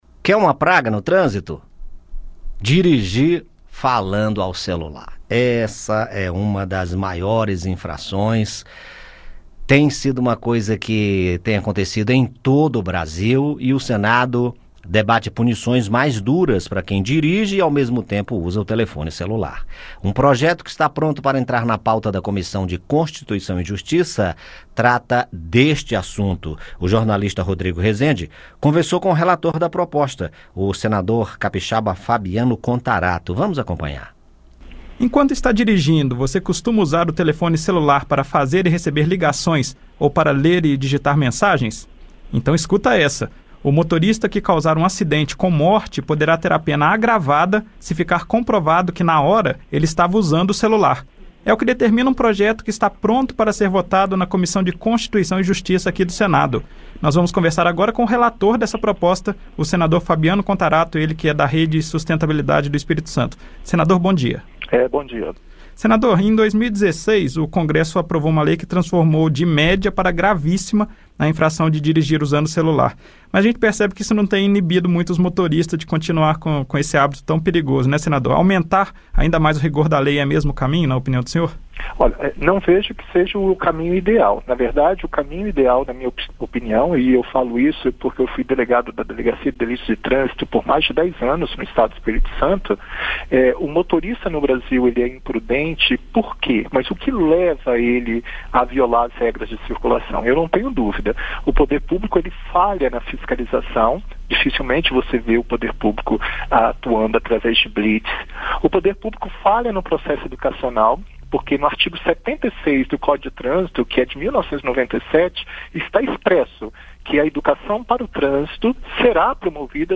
E, nesta sexta-feira (24), reprisamos entrevista de novembro de 2019 com o relator da proposta na CCJ, senador Fabiano Contarato (Rede–ES).